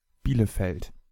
Bielefeld pronounced in German (native speaker)